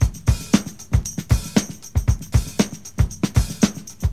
• 116 Bpm Drum Loop Sample C Key.wav
Free breakbeat - kick tuned to the C note. Loudest frequency: 1688Hz
116-bpm-drum-loop-sample-c-key-MCC.wav